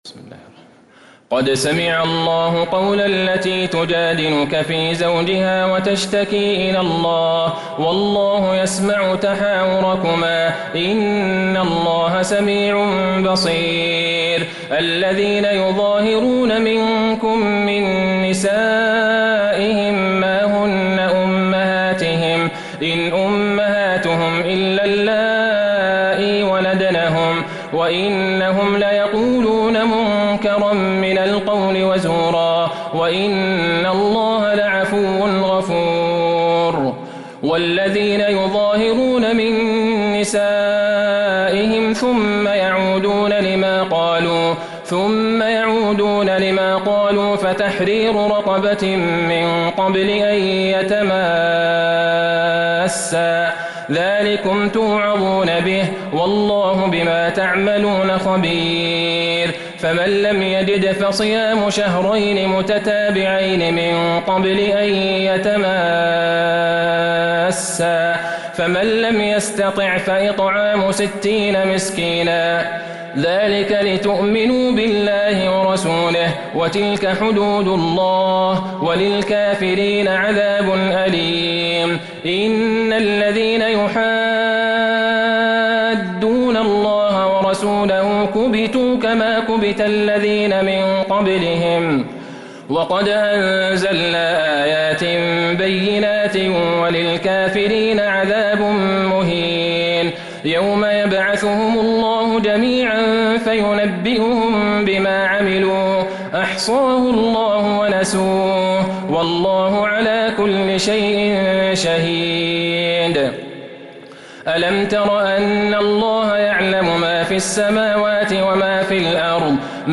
سورة المجادلة Surat Al-Mujadilah من تراويح المسجد النبوي 1442هـ > مصحف تراويح الحرم النبوي عام 1442هـ > المصحف - تلاوات الحرمين